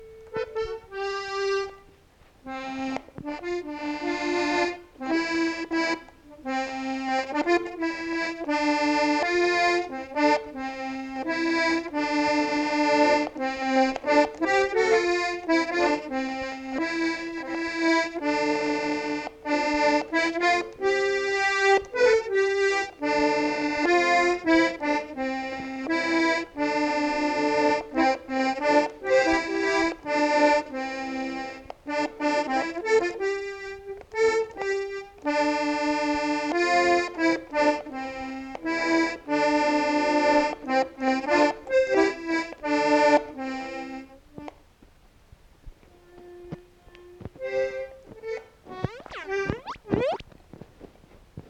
Genre : morceau instrumental
Instrument de musique : accordéon diatonique
Ecouter-voir : archives sonores en ligne